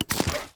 menu-edit-click.ogg